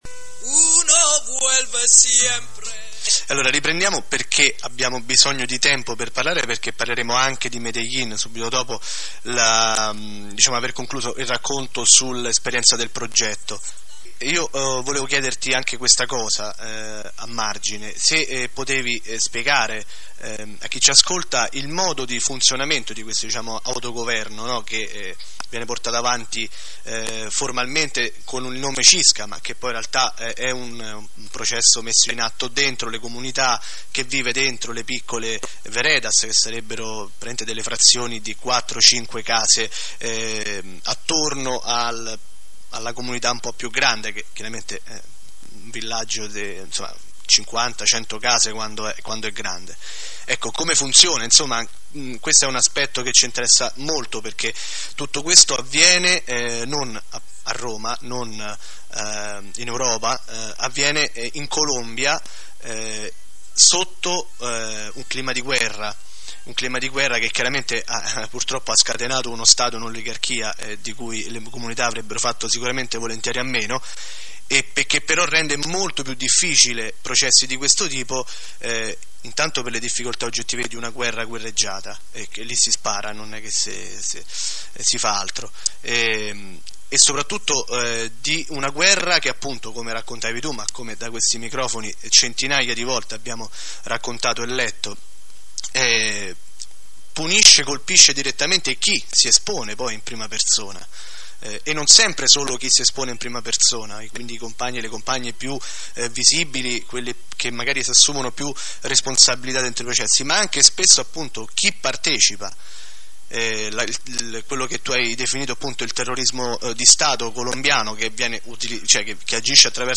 trasmissione del 2 aprile 2007